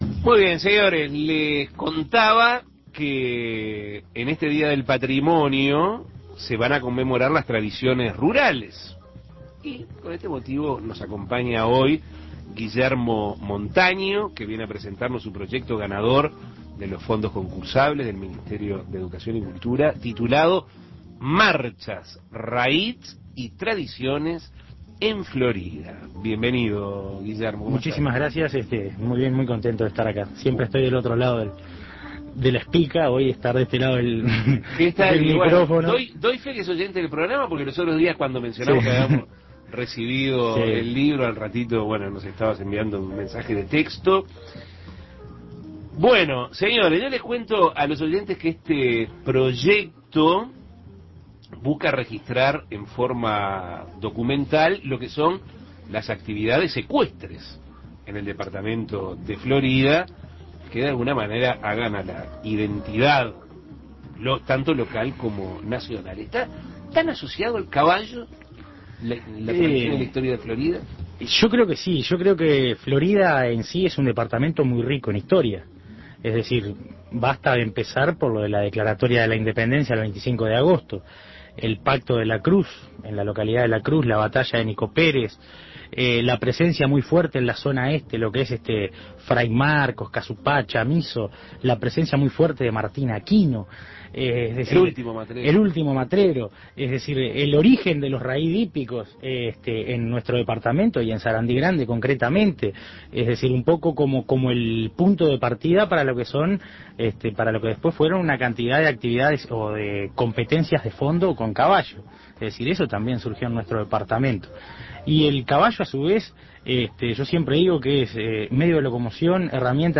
Este año el día del Patrimonio conmemora las tradiciones rurales. El edil de Florida, Guillermo Montaño, presentó en Asuntos Pendientes su proyecto ganador de los Fondos Concursables del MEC: Marchas, Raíds y tradiciones en Florida.
Entrevistas Patrimonio y tradiciones rurales Imprimir A- A A+ Este año el día del Patrimonio conmemora las tradiciones rurales.